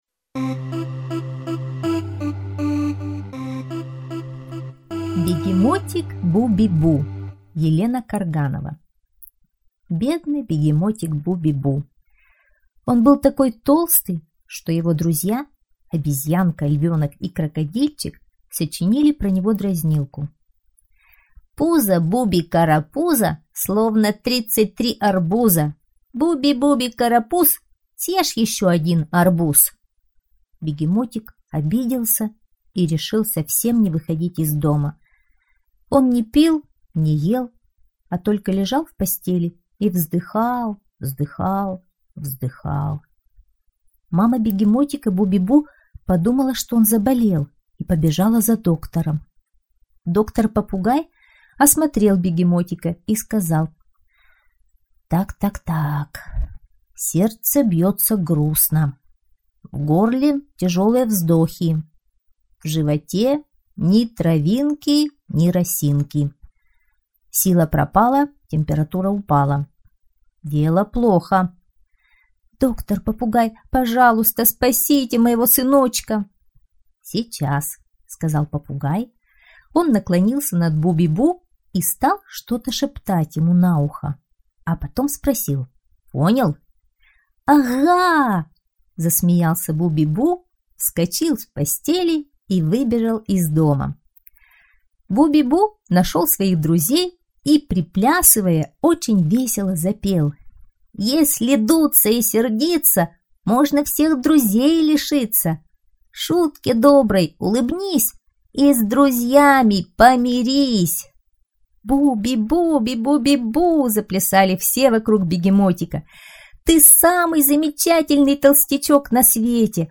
Бегемотик Буби-бу - аудиосказка Каргановой Е.Г. Бегемотик Буби-бу был такой толстый, что его друзья сочинили про него дразнилку.